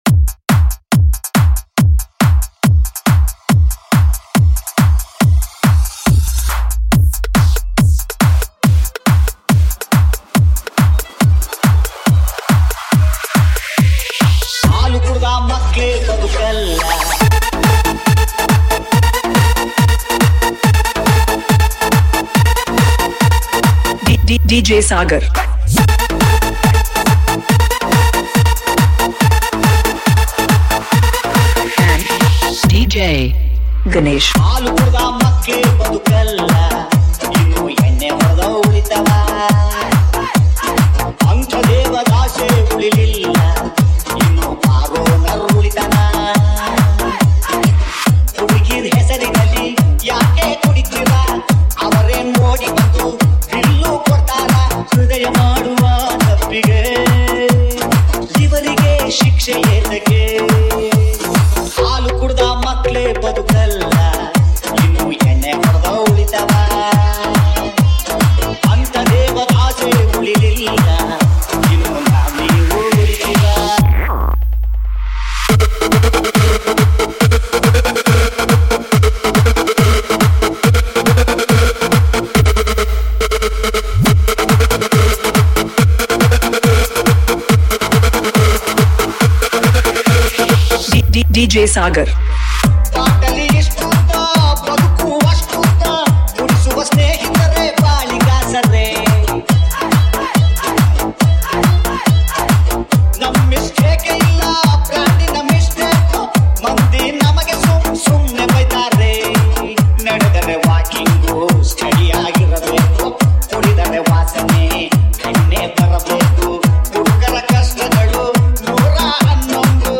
High quality Sri Lankan remix MP3 (3.7).